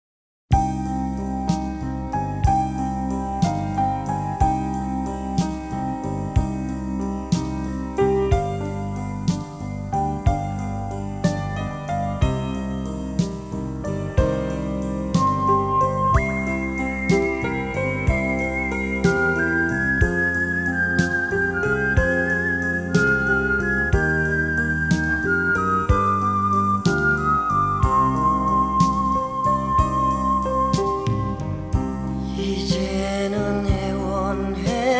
# Trot